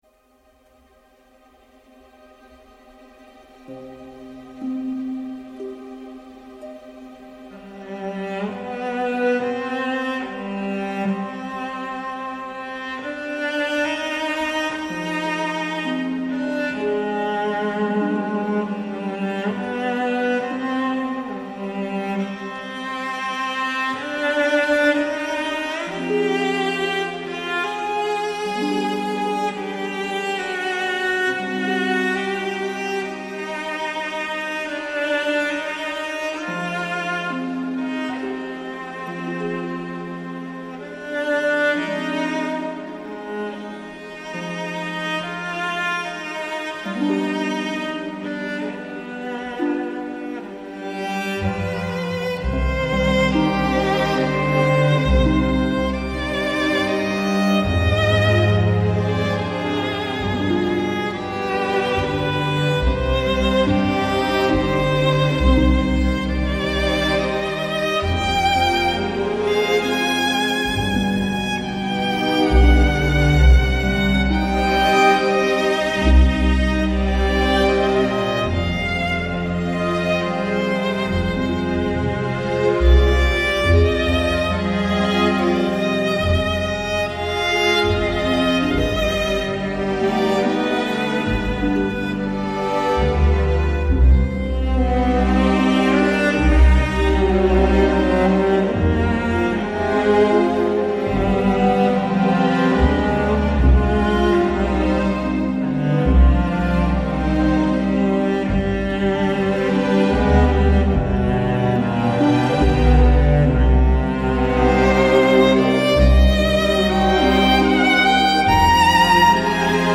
Στα δύο αυτά ραδιοφωνικά επεισόδια, οι ακροατές της ΕΡΤ και της Φωνής της Ελλάδας θα έχουν την ευκαιρία να ακούσουν τη συγκλονιστική προφορική μαρτυρία του Σπίλμαν που εξιστορεί όλα τα γεγονότα από την εισβολή των ναζί μέχρι την απελευθέρωση της Πολωνίας.